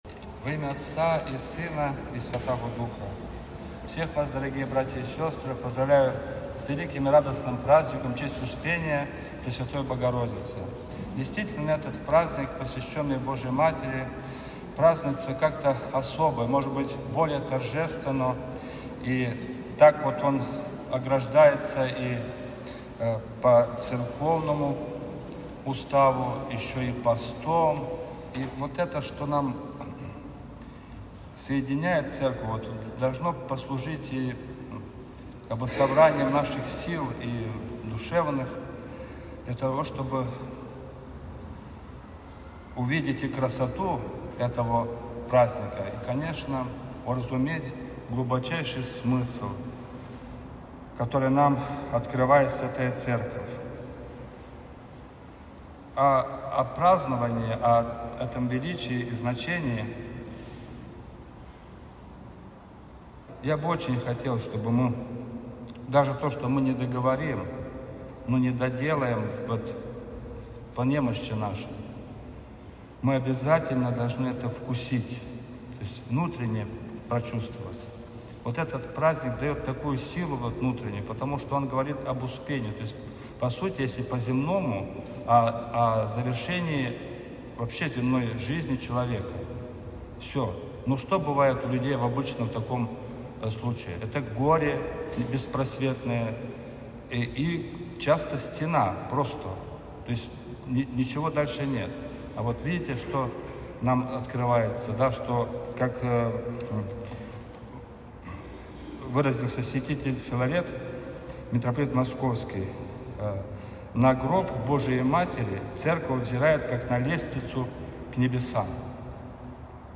Проповедь
Божественная-литургия-5.mp3